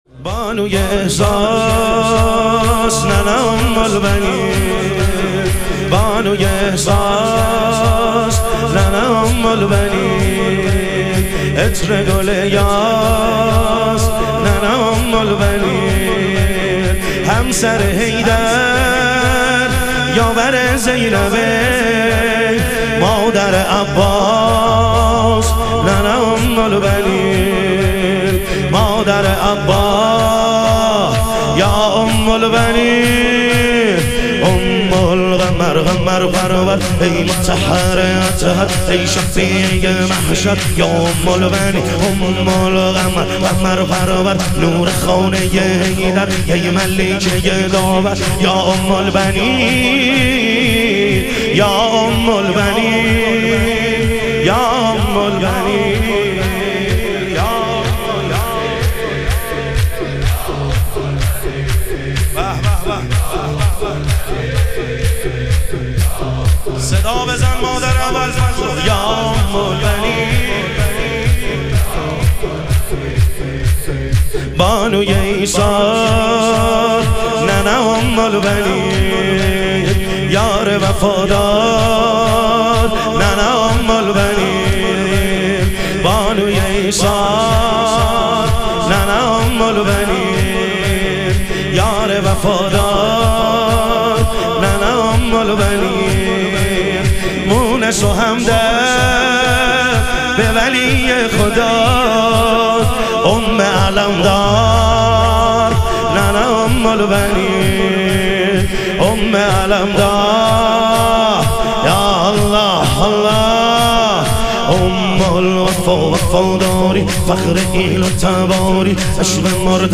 شور
شب شهادت حضرت ام البنین علیها سلام